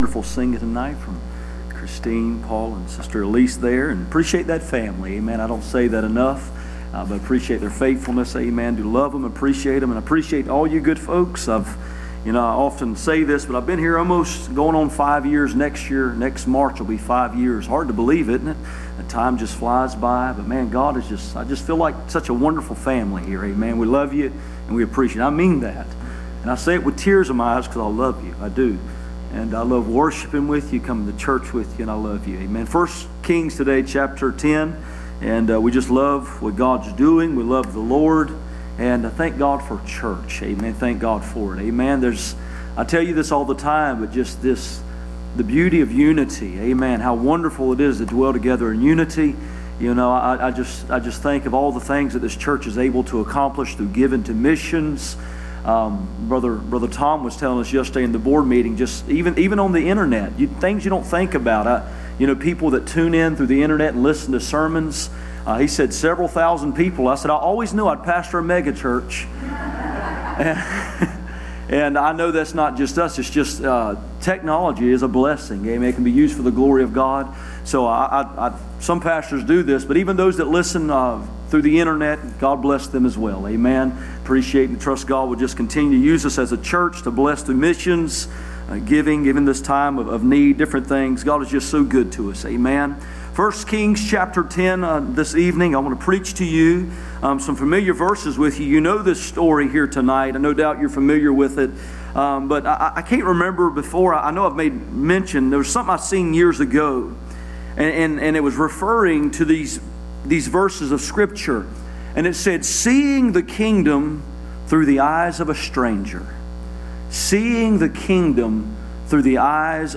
Passage: 1 Kings 10:1-10, Matthew 12:42 Service Type: Sunday Evening